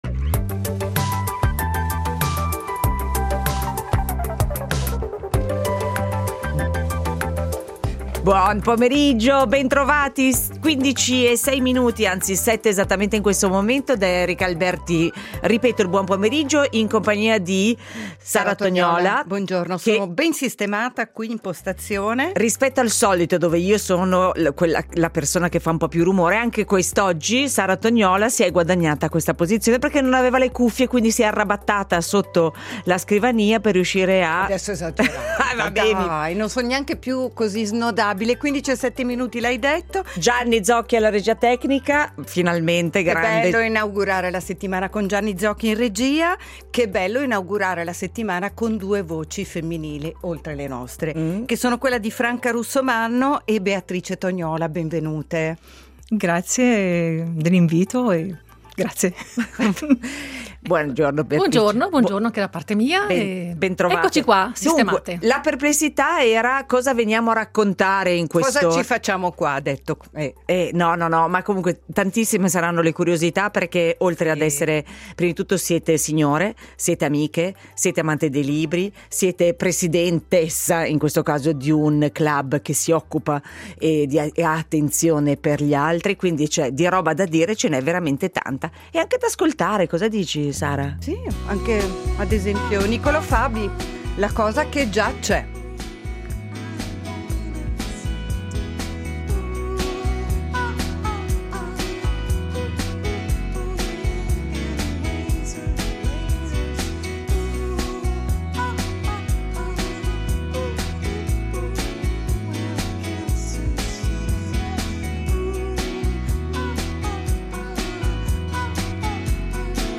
In studio con noi